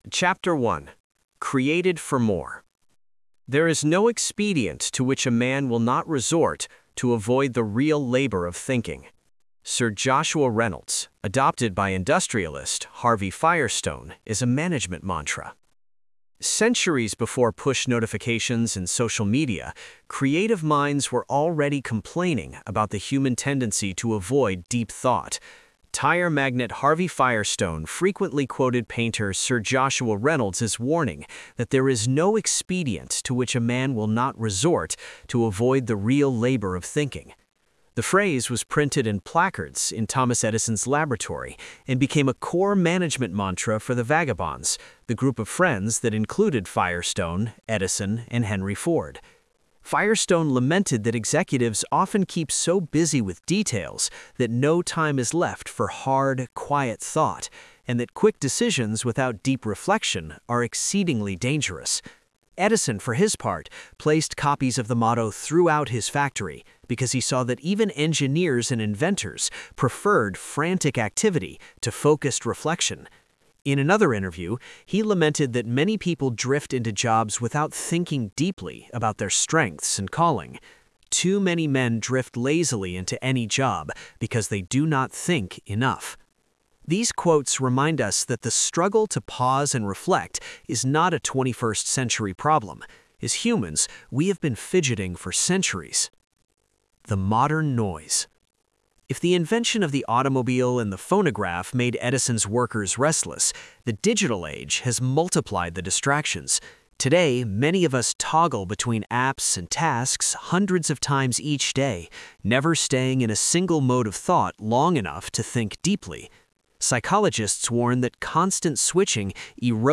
Book
The-Stewardship-Blueprint-Male-Version-1.wav